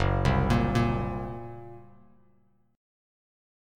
F#Mb5 chord